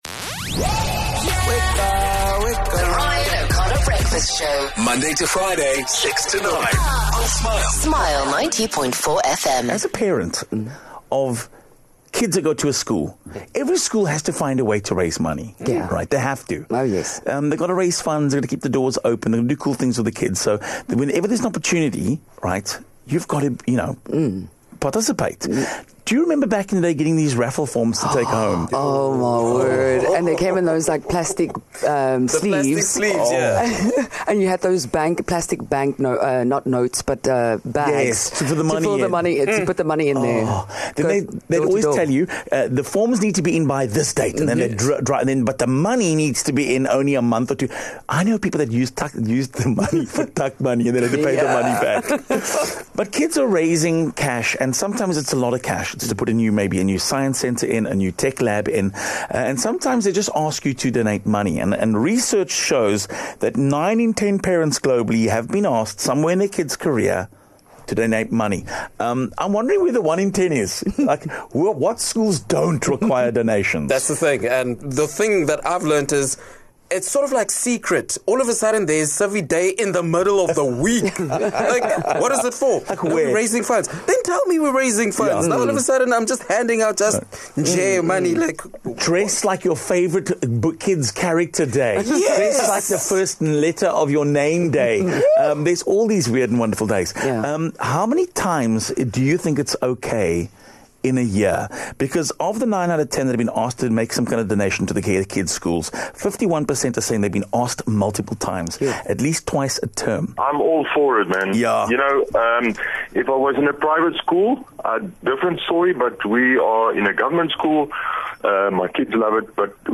A lot of us find ourselves in the position of forking out a bit more money for school than what we planned for. We got the perspective of parents, a school principal, and comedian & father Stuart Taylor.